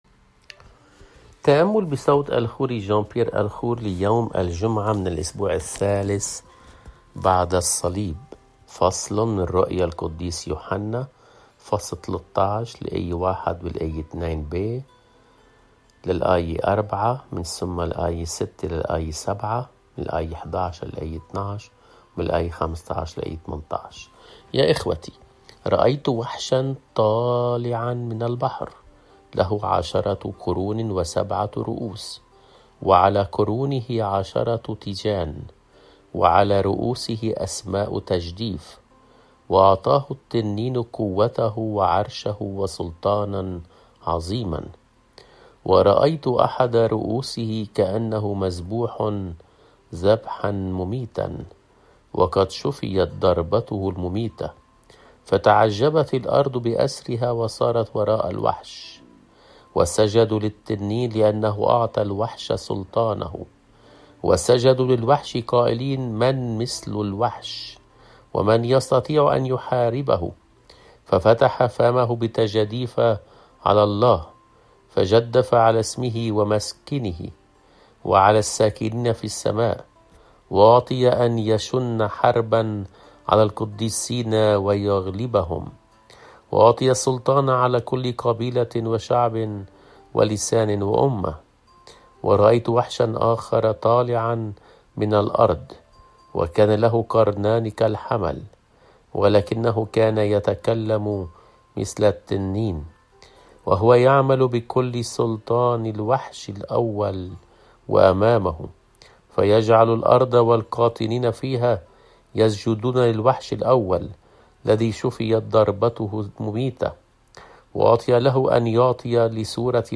الرسالة والإنجيل